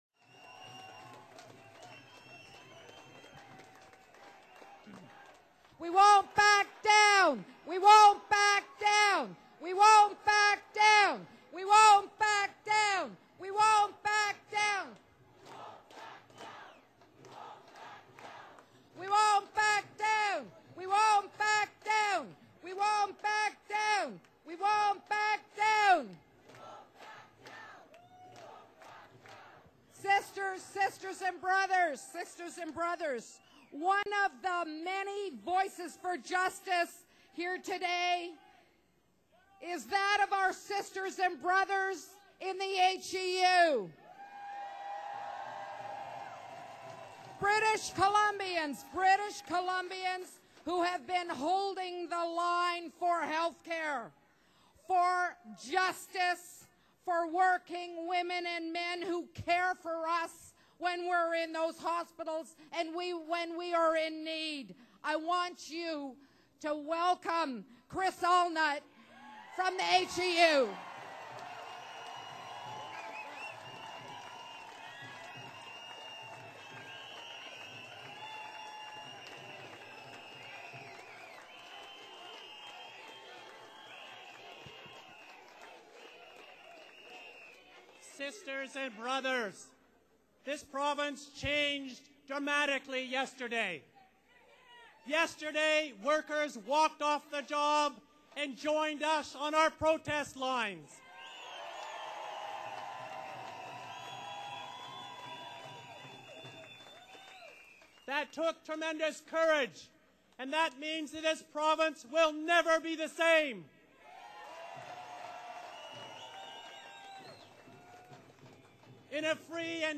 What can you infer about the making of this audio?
MAYDAY RALLY: "Voices for Justice" at the Vancouver Art Gallery, culmination of Mayday march.